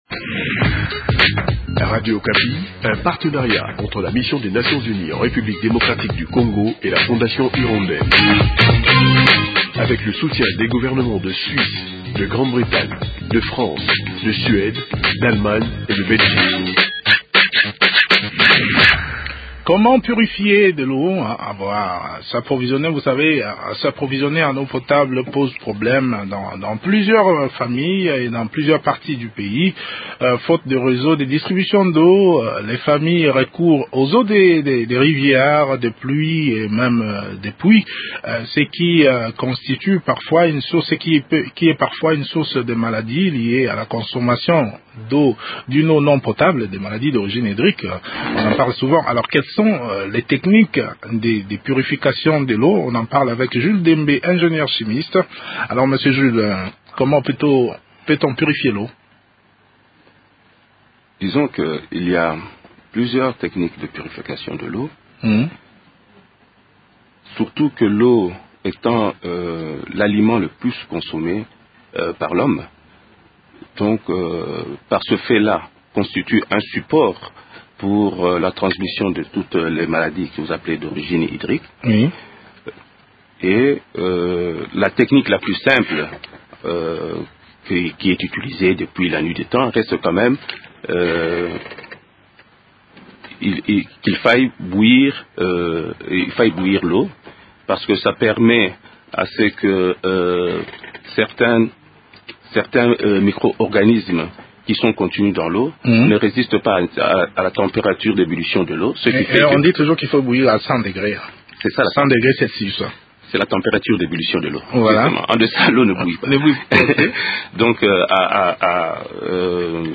ingénieur chimiste.